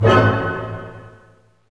Index of /90_sSampleCDs/Roland - String Master Series/HIT_Dynamic Orch/HIT_Orch Hit dim
HIT ORCHDI0E.wav